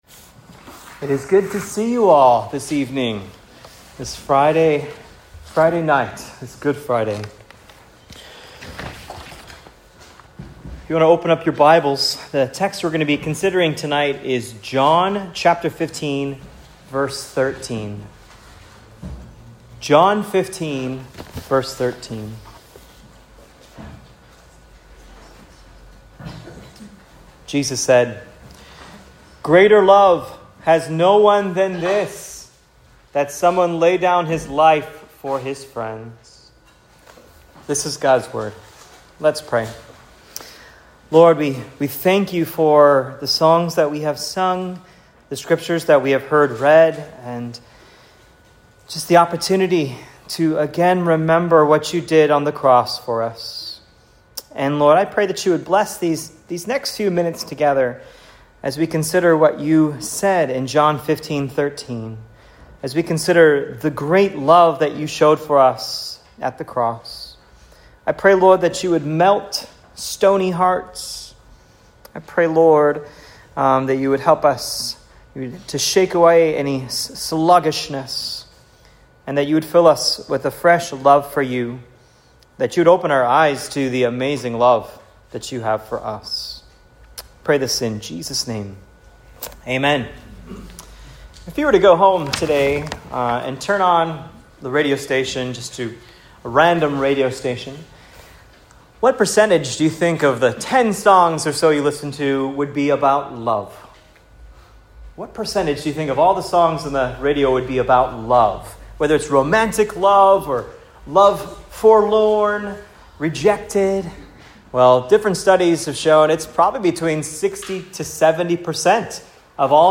Good Friday Sermon (2024): Love Incomparable